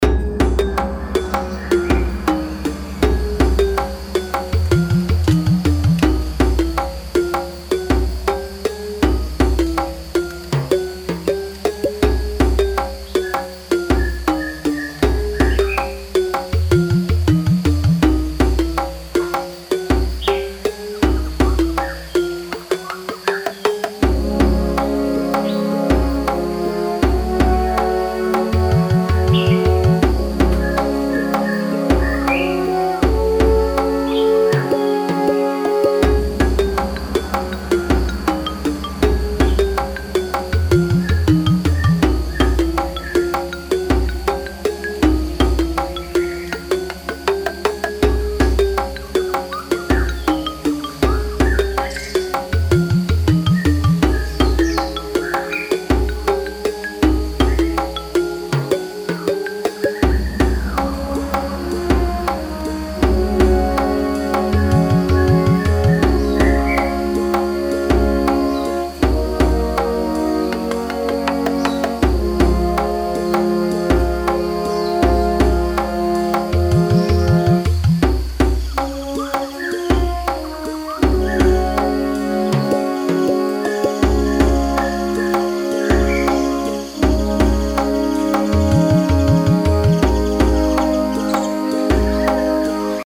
2. Add a music layer
Watermark_Music01.mp3